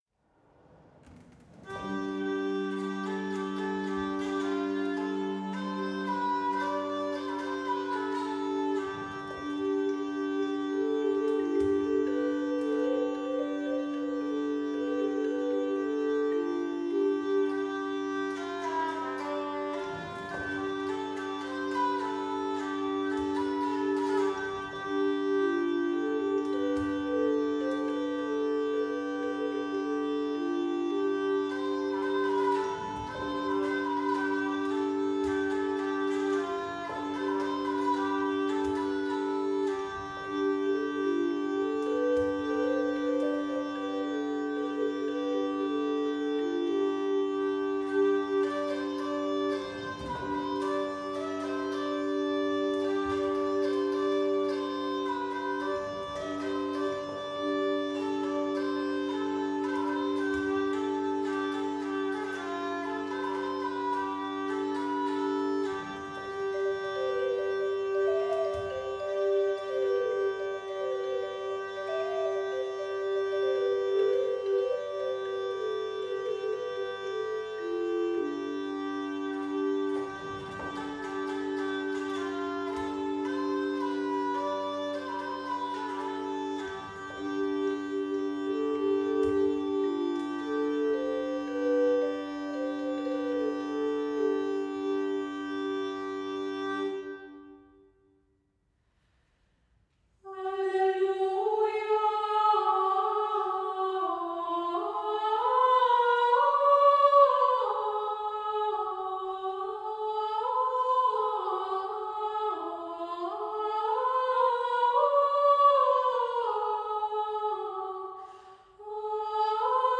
Płyta „Eya recolamus” / Sekwencje w XIV-wiecznym Krakowie
Żeński zespół wokalny FLORES ROSARUM serdecznie zaprasza na koncert promujący płytę „Eya recolamus”.